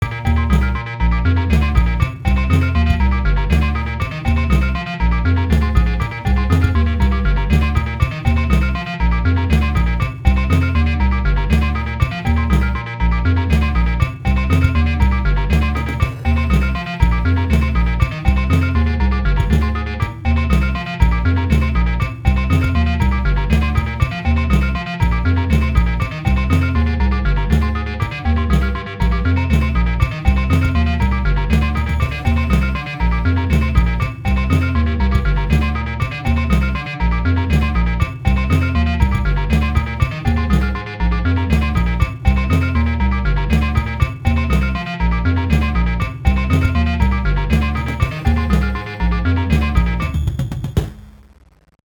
It's a single Fugue Machine pattern, the bright bell like plonks (Dixie) is that pattern 1:1 and forwards. The bass (Zeeon) is that pattern backwards and half speed and two octaves lower.
Logan set to follow the AUM track with only kick and snare on and those drums are then bitcrushed to heck.